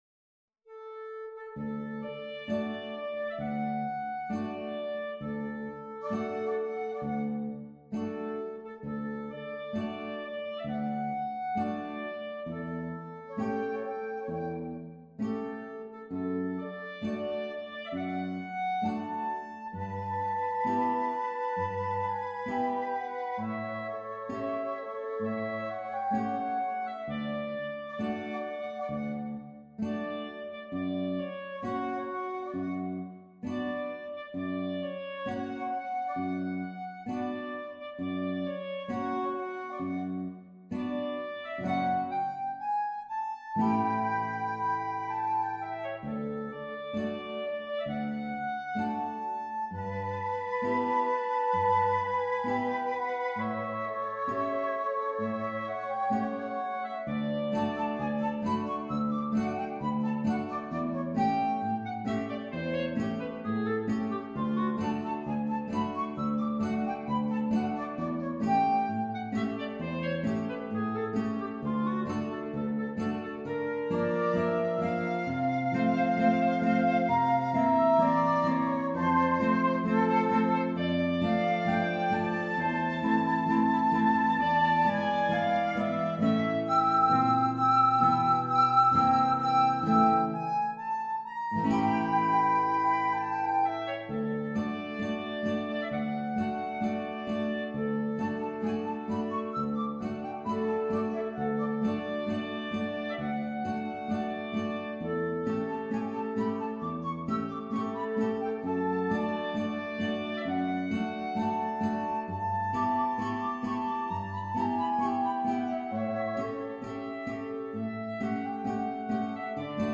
per flauto, clarinetto in La e chitarra